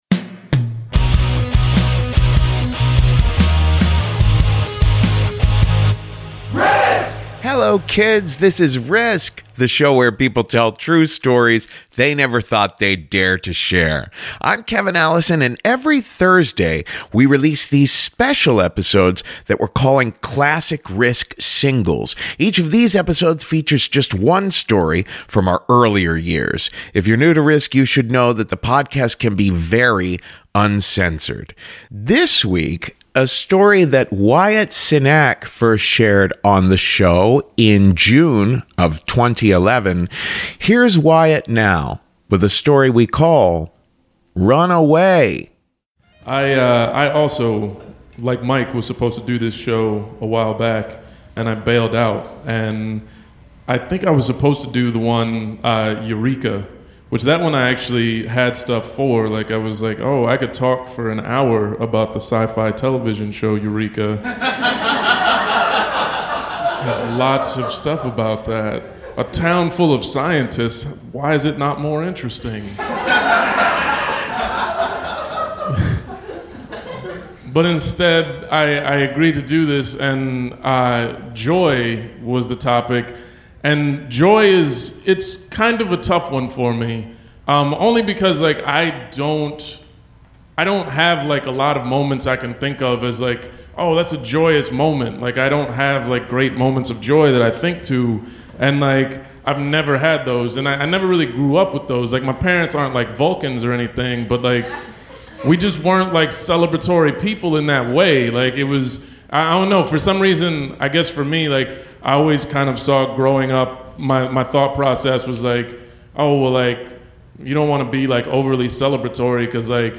A story that Wyatt Cenac first shared on the podcast in June of 2011 about attending a spiritual retreat in his high school years.